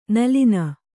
♪ nalina